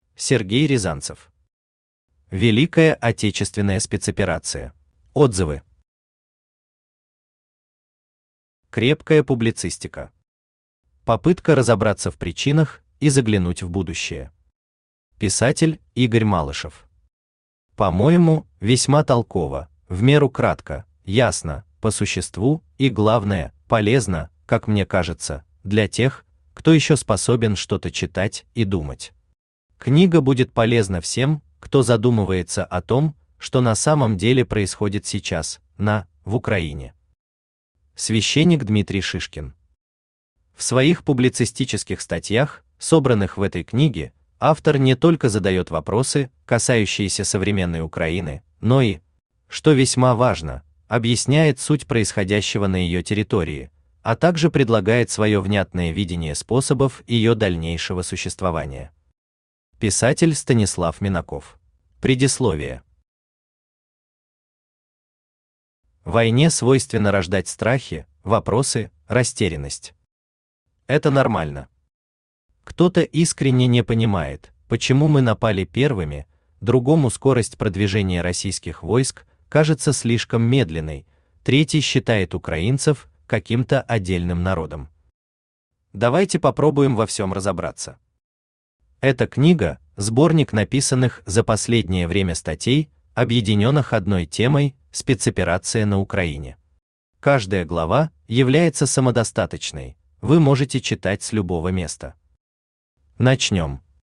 Aудиокнига Великая Отечественная Спецоперация Автор Сергей Рязанцев Читает аудиокнигу Авточтец ЛитРес.